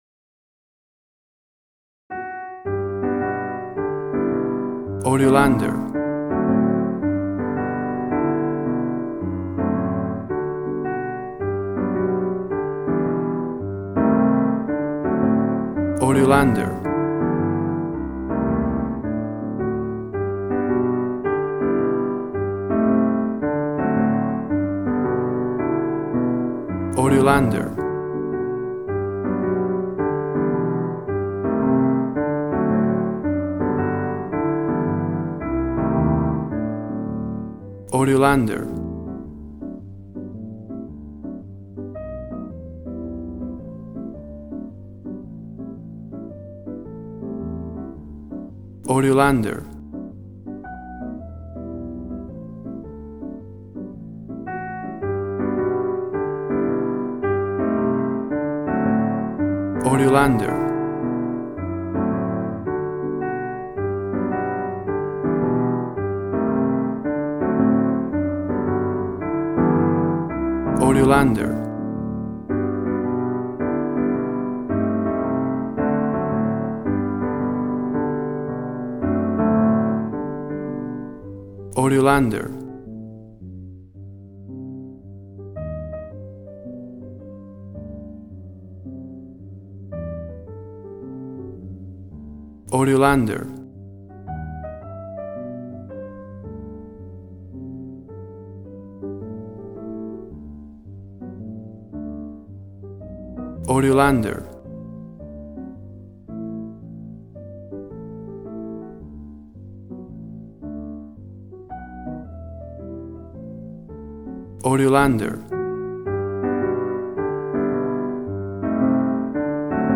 Smooth jazz piano mixed with jazz bass and cool jazz drums.
Tempo (BPM): 100